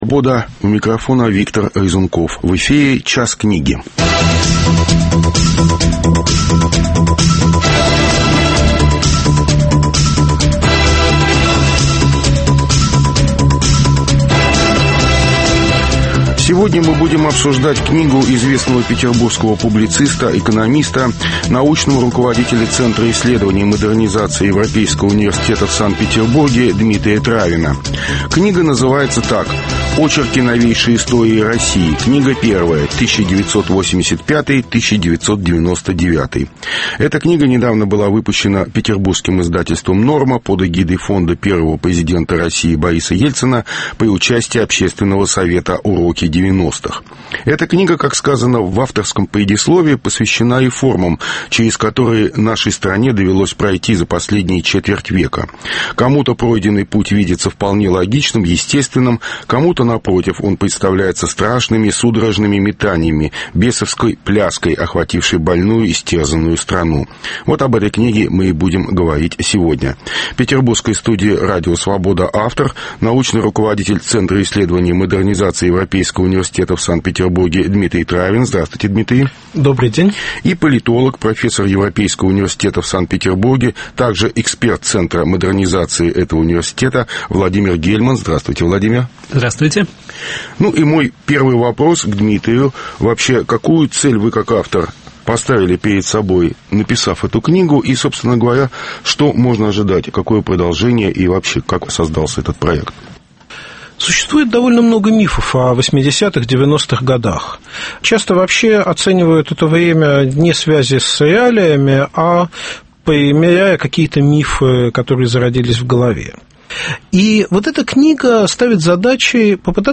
(В записи).